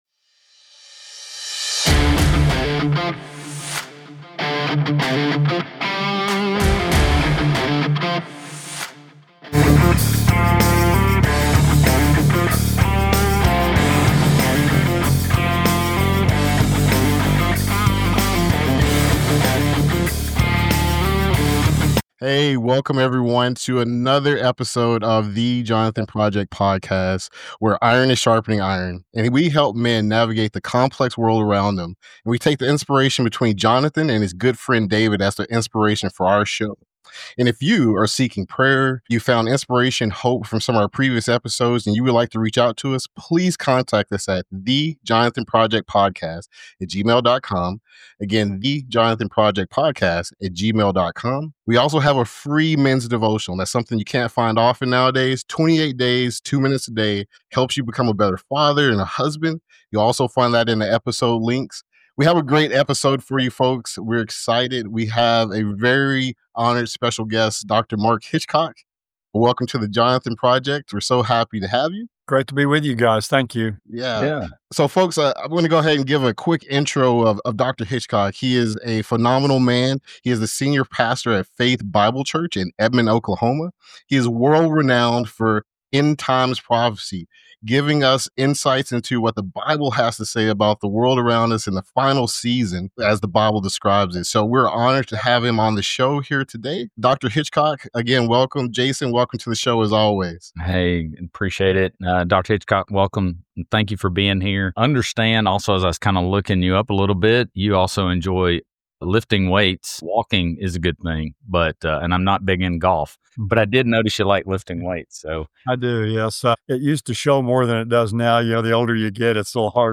conversation on what Bible prophecy means for Christian men today